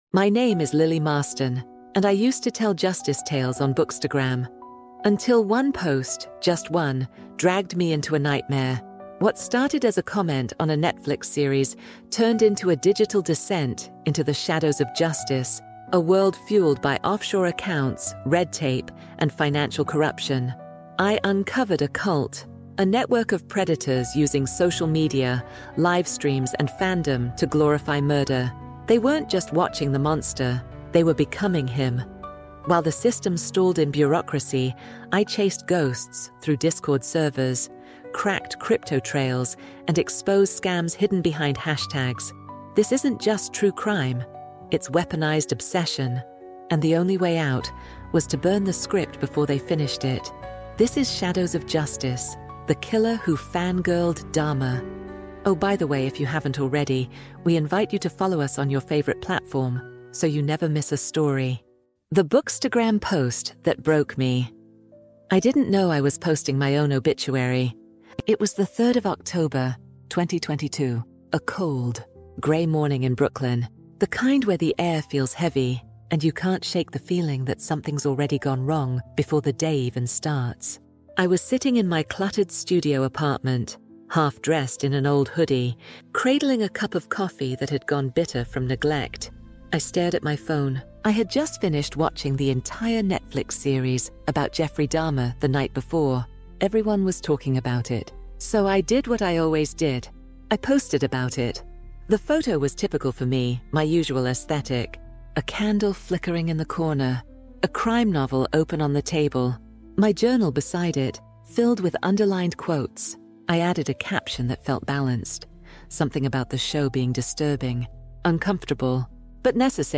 True Crime | Shadows of Justice | The Killer Who Fan-Girled Dahmer | Audiobook
This message propels her into a clandestine network of digital predators who idolize serial killers, leading to a web of financial corruption, offshore accounts, and crypto-backed executions. Through emotionally charged, first-person narration enhanced by AI voiceover realism, "Shadows of Justice" immerses listeners in a world where fandom turns fatal, and the quest for justice exposes the broken systems that enable such horrors.